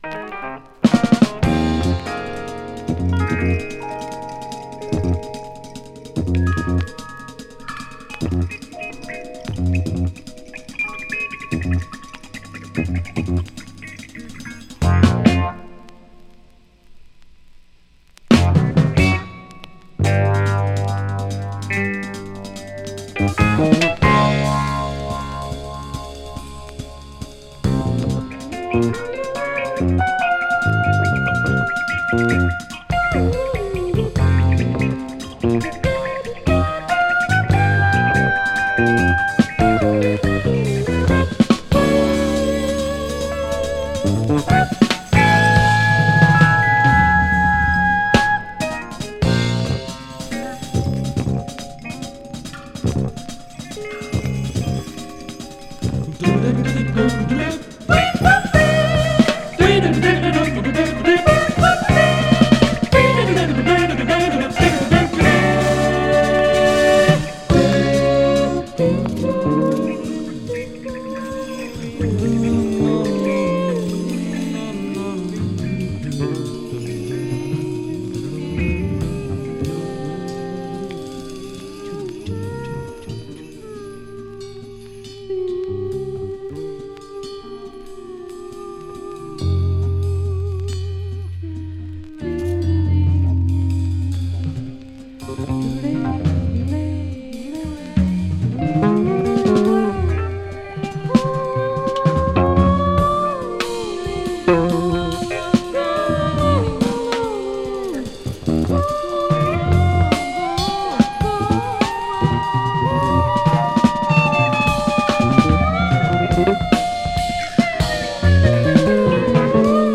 ポーランドのコーラス・グループ